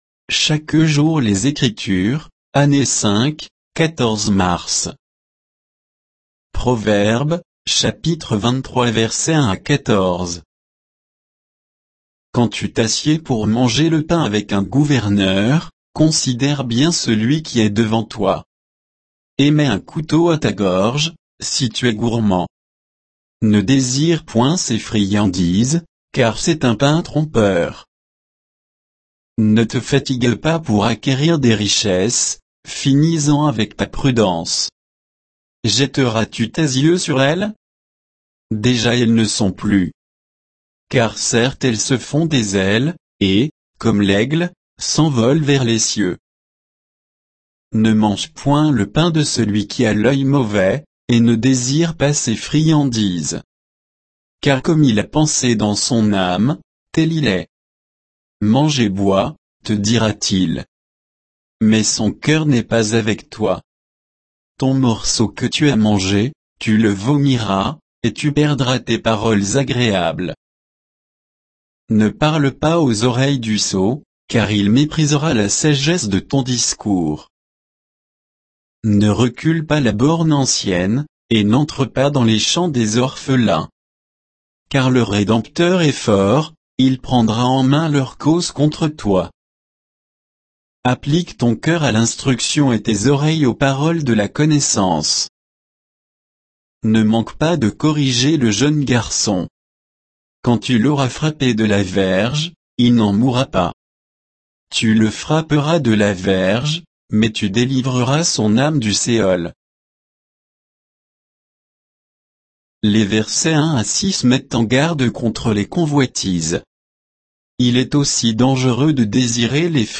Méditation quoditienne de Chaque jour les Écritures sur Proverbes 23, 1 à 14